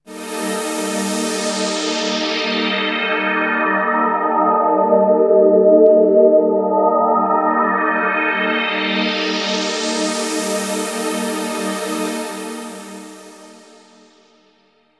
Ambient / Sphere / SYNTHPAD104_AMBNT_160_C_SC3(R).wav
1 channel